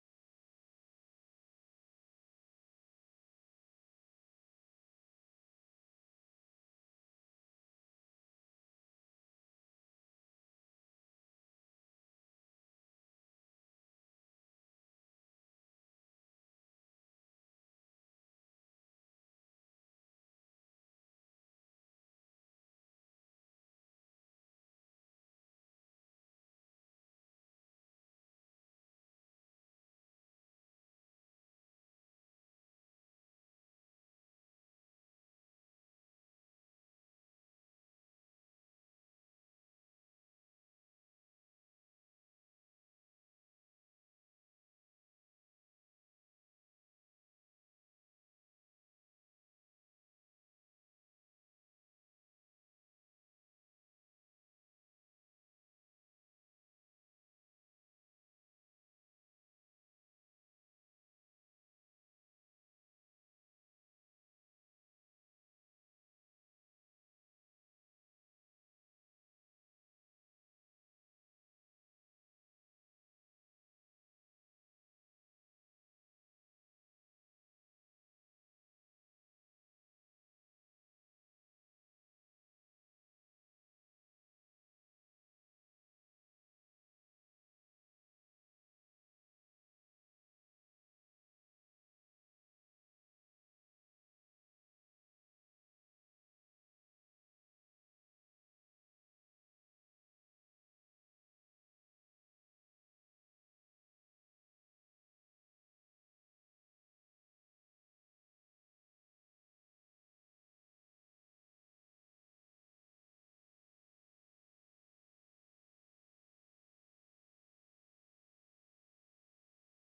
VIRTUAL LEARNING LAB ARCHIVE